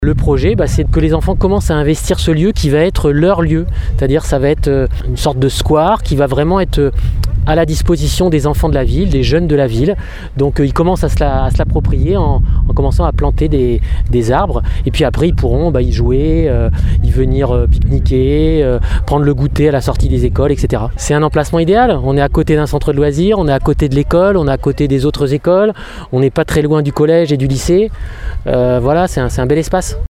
L’objectif étant d’en faire à terme un square dédié aux enfants, comme le souligne Laurent Rouffet, adjoint au maire en charge de l’Éducation :